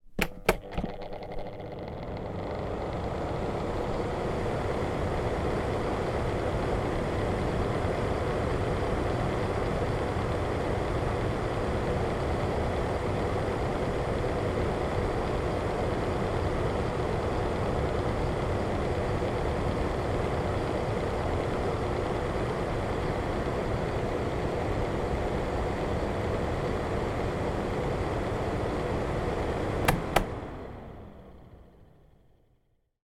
На этой странице собраны звуки работающего очистителя воздуха – монотонный белый шум, напоминающий легкий ветер.
Мощный поток воздуха вырвался из очистителя после включения